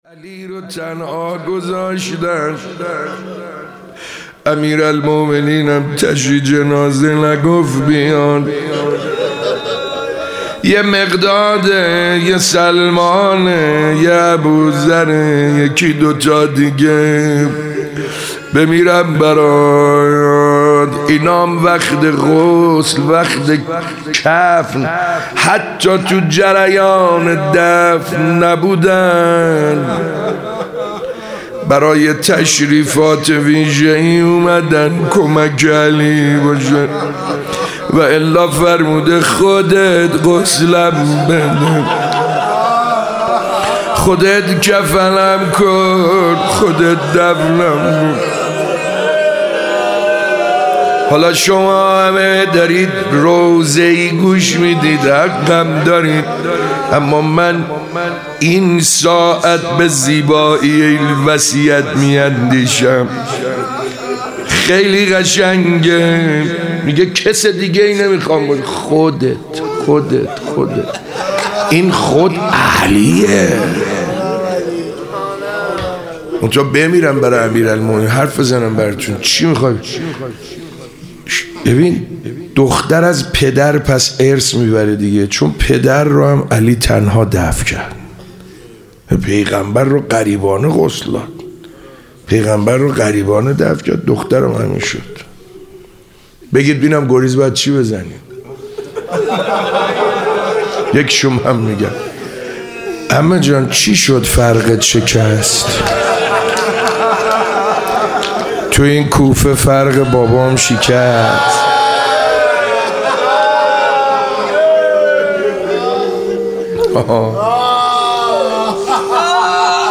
فاطمیه دوم 96- روز چهارم - روضه خوانی